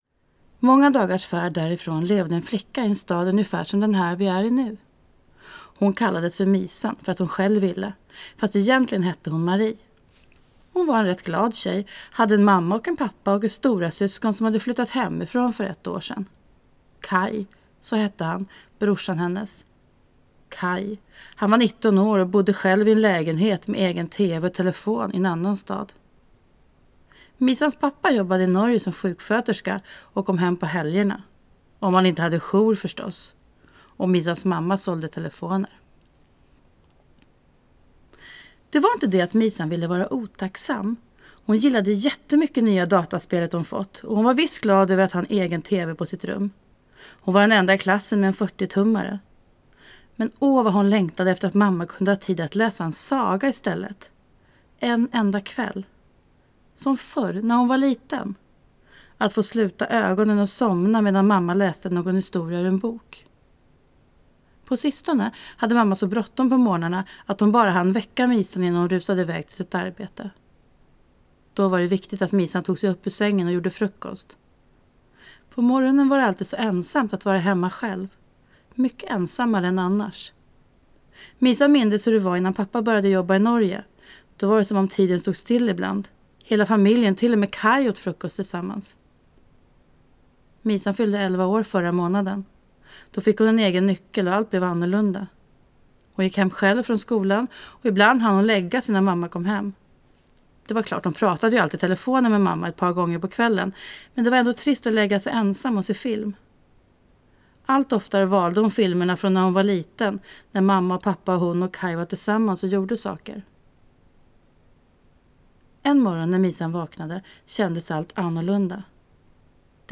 Jag gillar rytmen och rösten, jag gillar också berättelsen om tjejen som inte längre blir sedd.